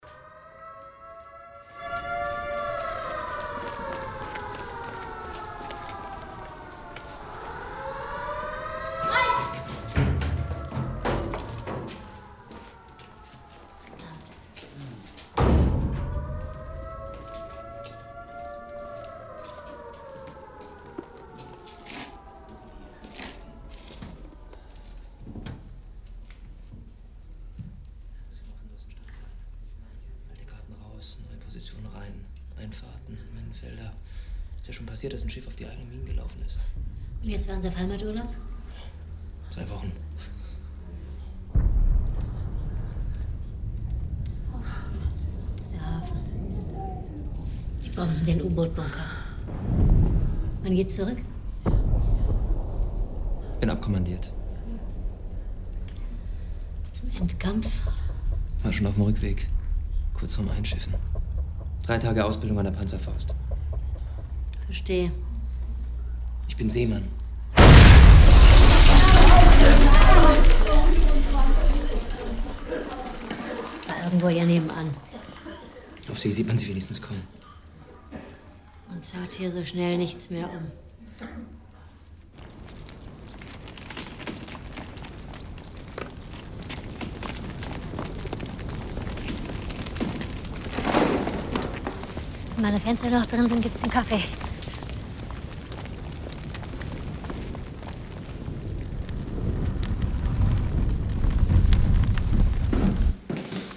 Dialog im Luftschutzkeller
Dialog im Luftschutzkeller.MP3